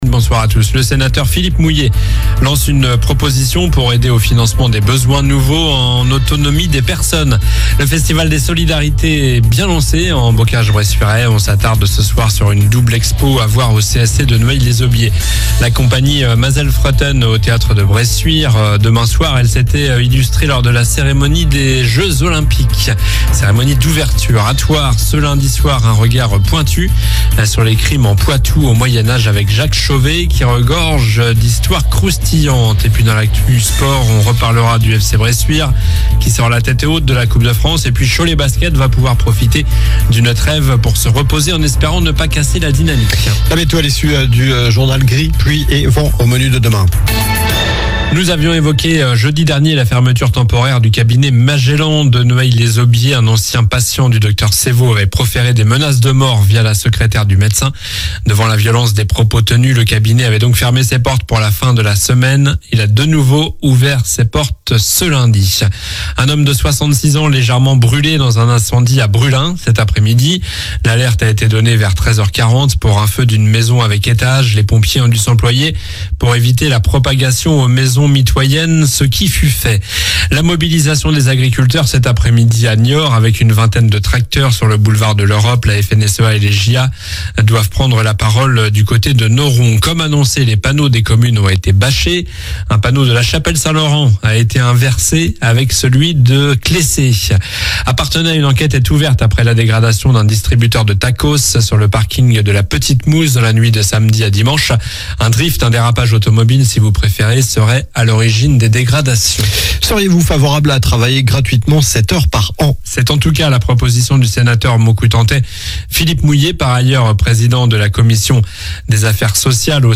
Journal du lundi 18 novembre (soir)